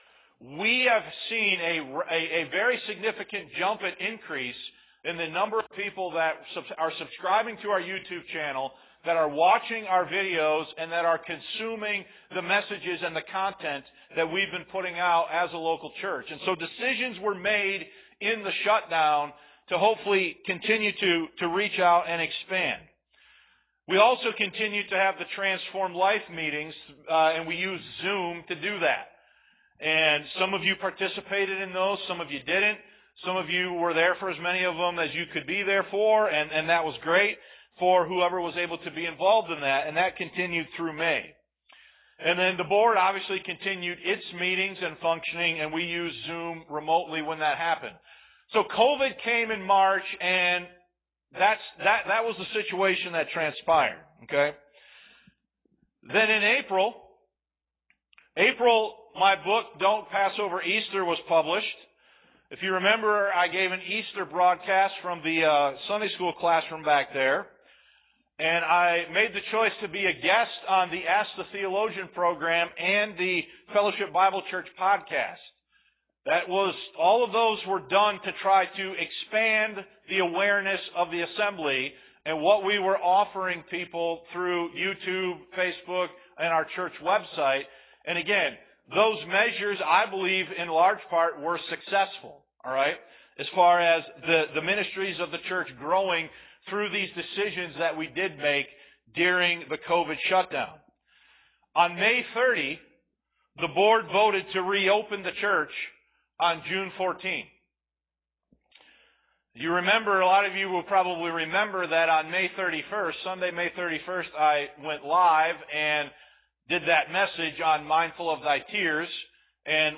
Audio kicks in around the 15 minute mark.